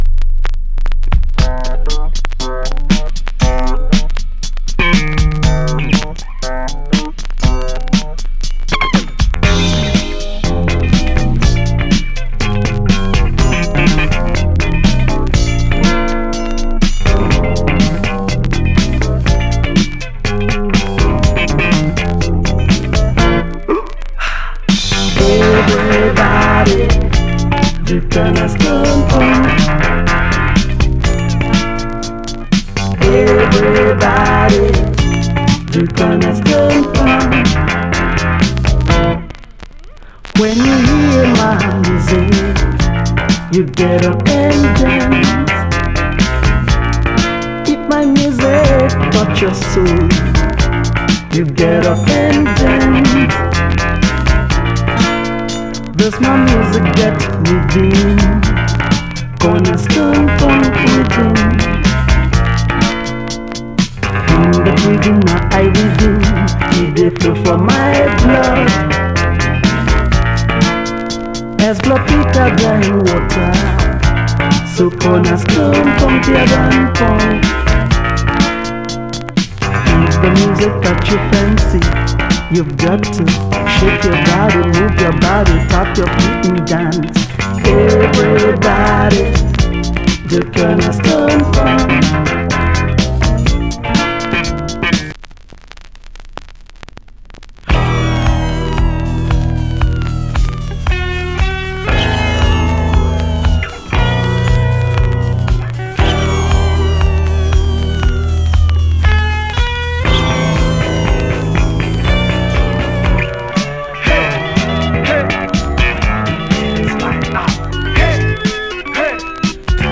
Genre: Funk / Soul Style: Boogie, Disco, Funk